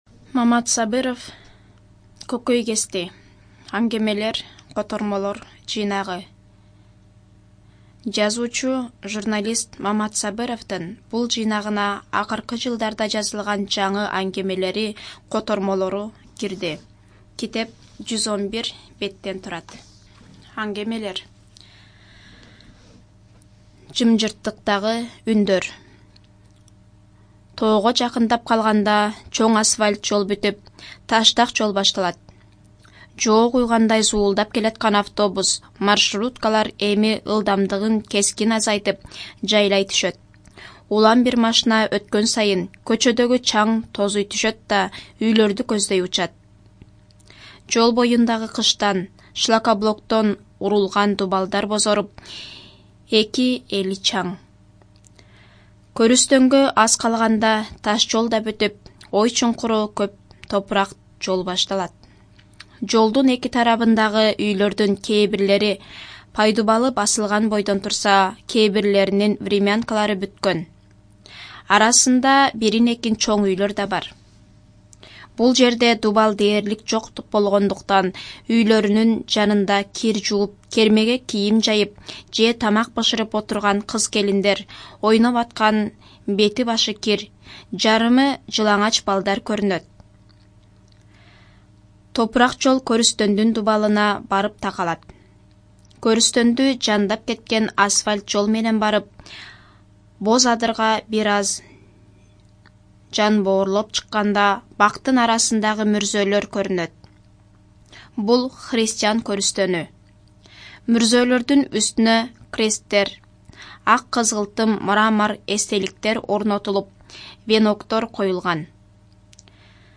Студия звукозаписиКыргызская Республиканская специализированная библиотека для слепых и глухих